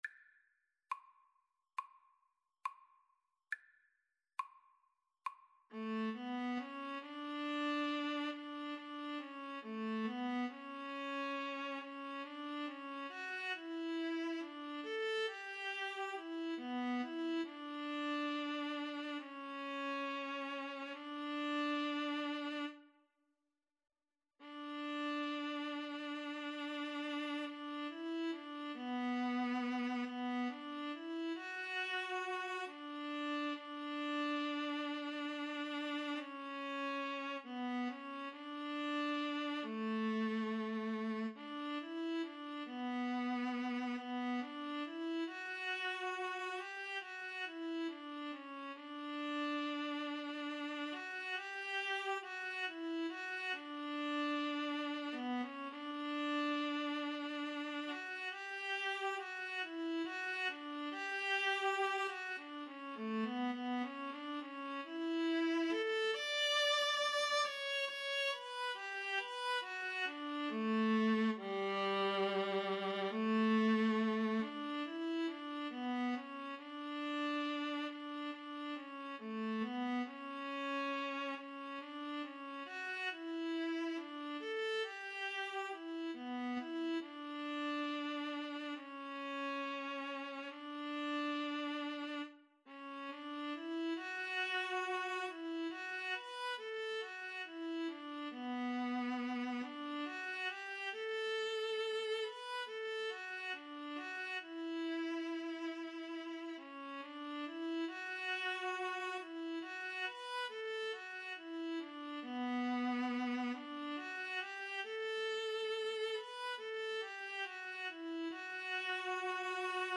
Espressivo = c. 69
Classical (View more Classical Violin-Viola Duet Music)